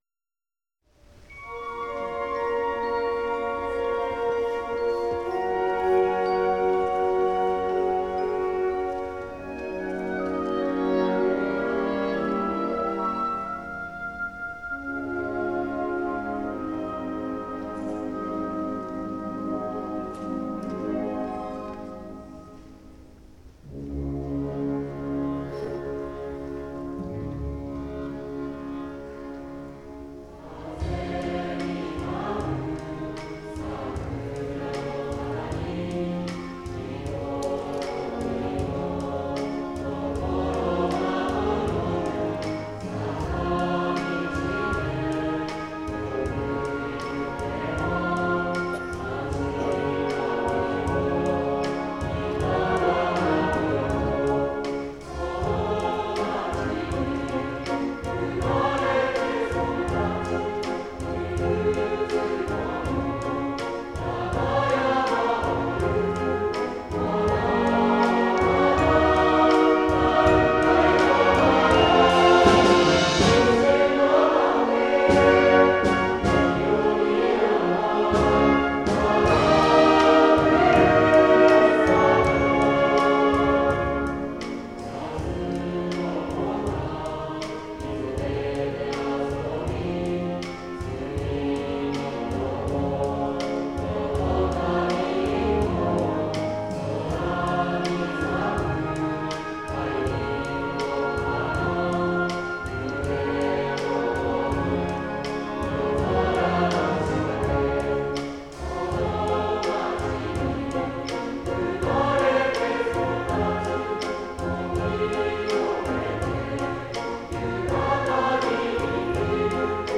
吹奏楽 なつかしバージョン
D-flat Major
３部合唱（斉唱・独唱も可）
（合唱・吹奏楽とも）
オーボエとハープにソロがあります。